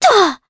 Juno-Vox_Landing_jp.wav